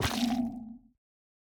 Minecraft Version Minecraft Version 1.21.5 Latest Release | Latest Snapshot 1.21.5 / assets / minecraft / sounds / block / sculk_sensor / place5.ogg Compare With Compare With Latest Release | Latest Snapshot